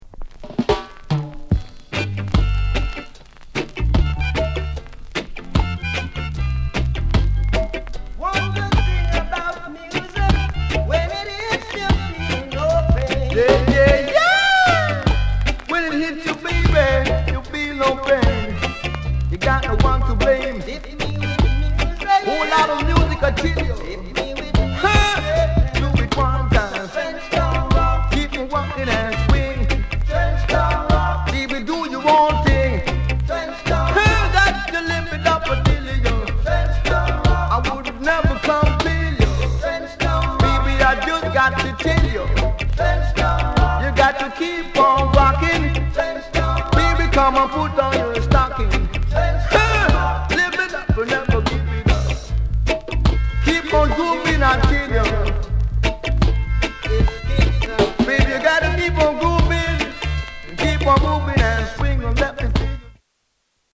SOUND CONDITION A SIDE VG(OK)